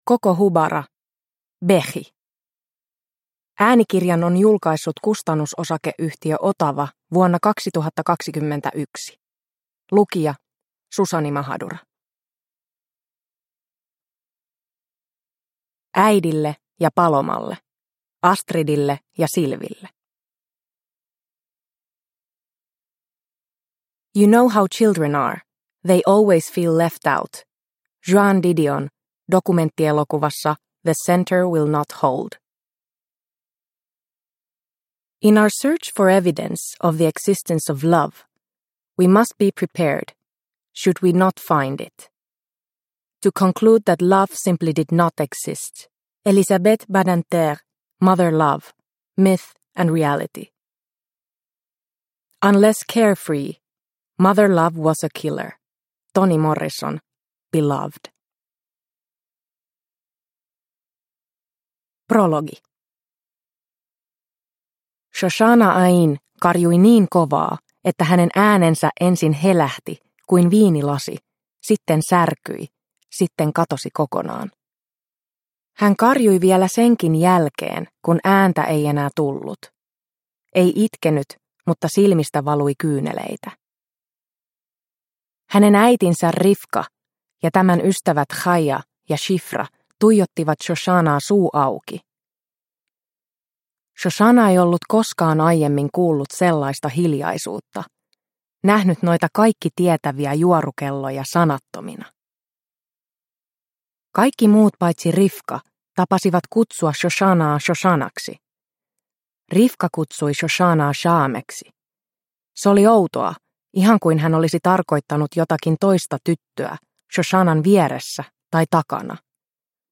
Bechi – Ljudbok – Laddas ner